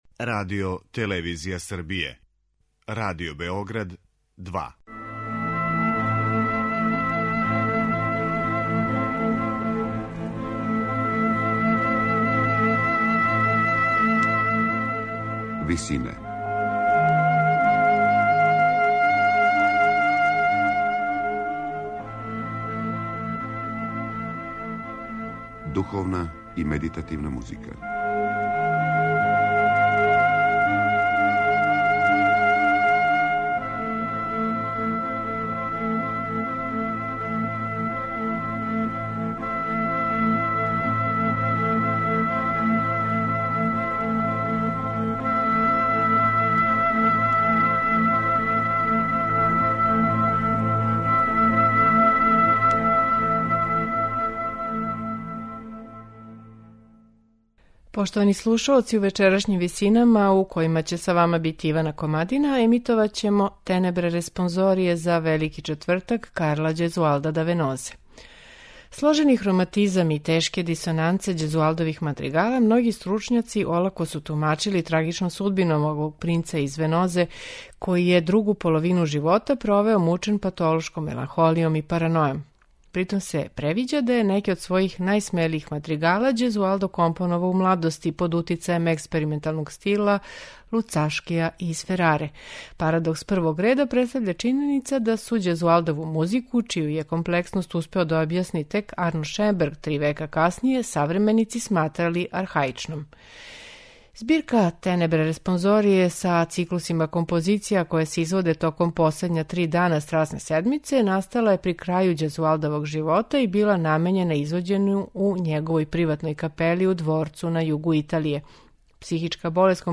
У вечерашњим Висинама, слушаћемо Ђезуалдове "Тенебре респонзорије" за Велики четвртак, у интерпретацији ансамбла A sei voci.